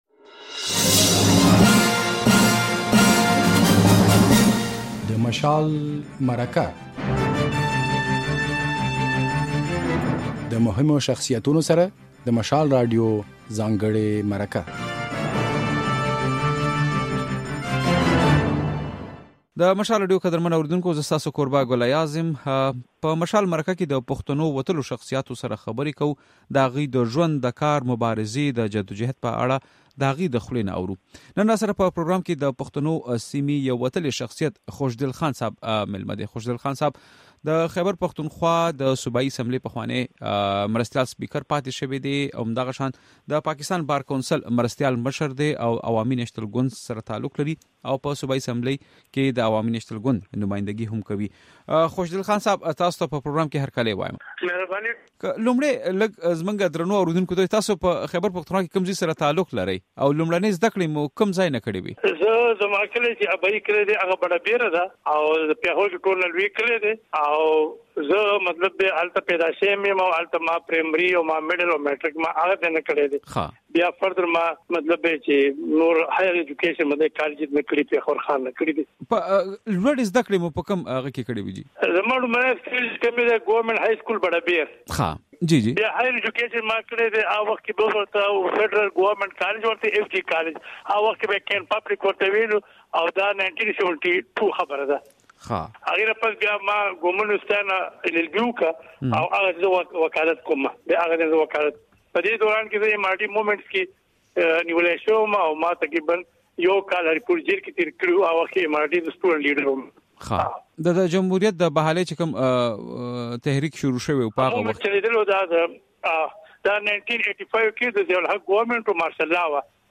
ځانګړې مرکه کړېده.